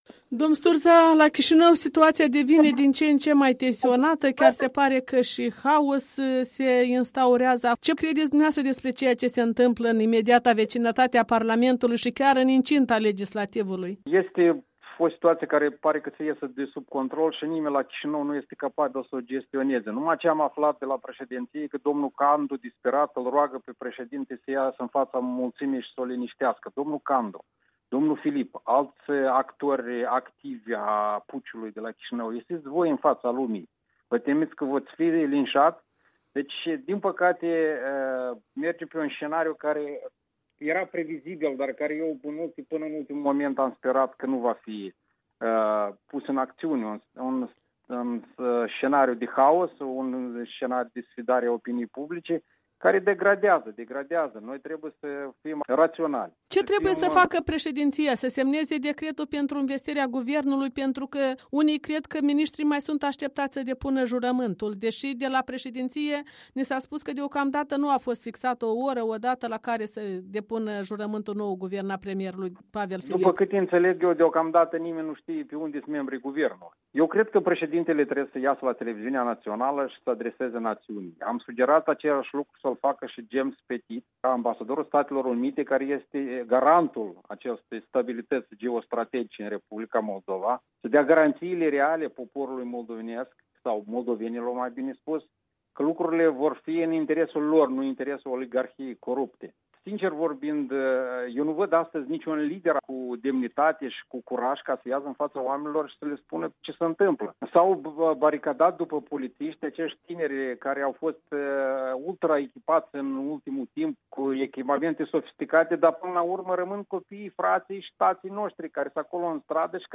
Opinii și comentarii în dialog cu Ion Sturza